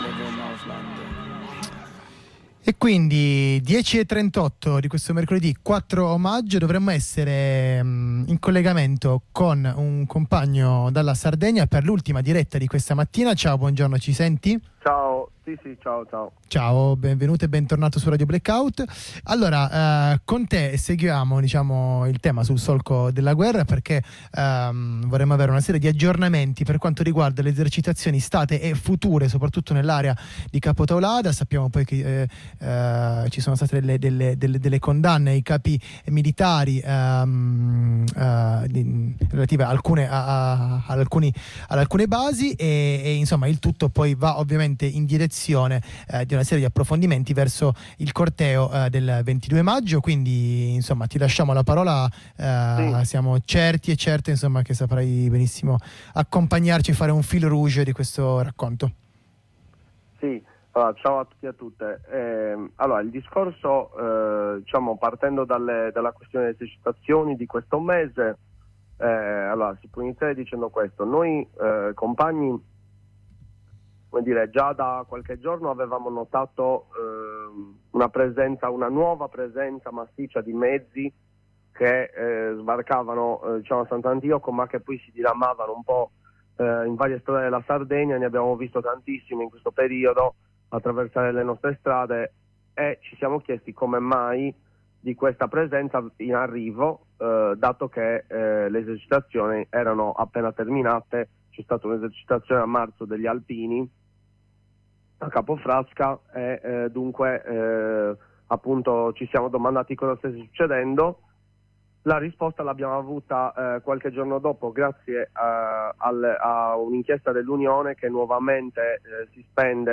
Di tutto questo, e soprattutto delle prospettive di lotta future, tra cui il corteo contro le basi del 22 Maggio, legate anche ai venti di guerra che spirano forti, ce ne parla un compagno Sardo. Un Maggio denso di iniziative contro la militarizzazione dell’isola Sarda.